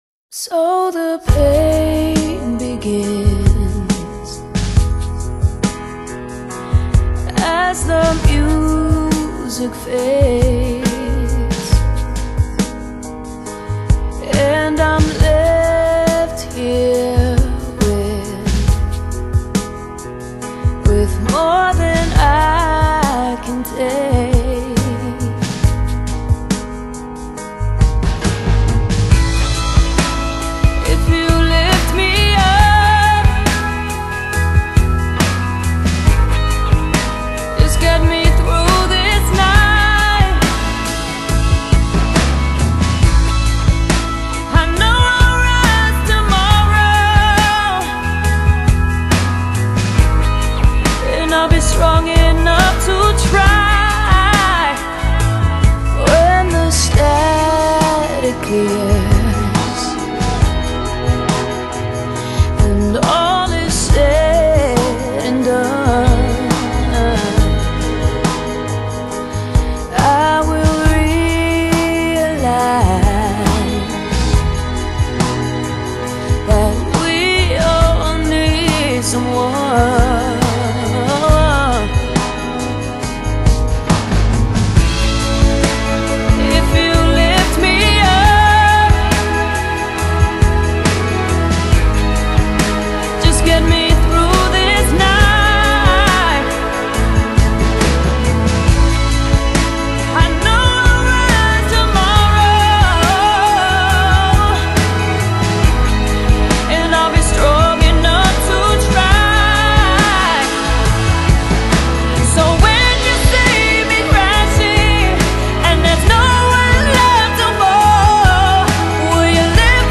【流行歌后2010新碟】